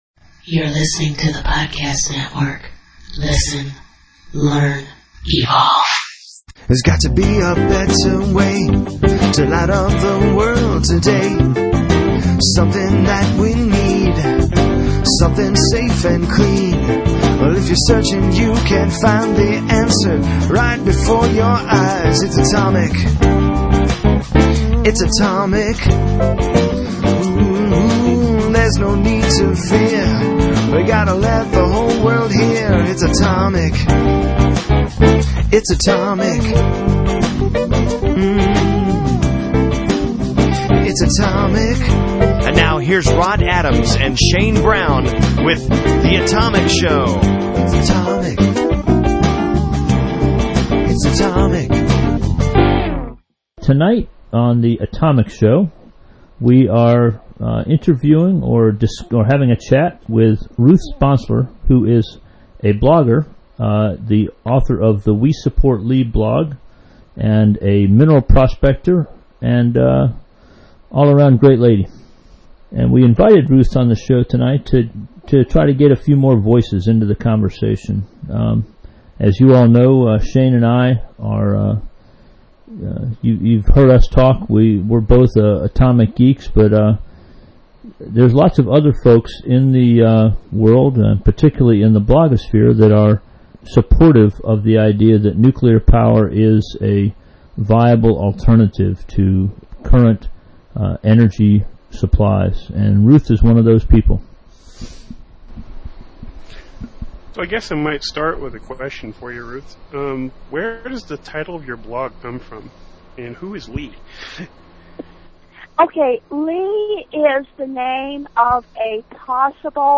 Chat with a uranium mineral collector and nuclear power supporter
Following the show, you will hear a short promo for Hobo Radio , part of The Podcast Network line up of independently produced podcasts.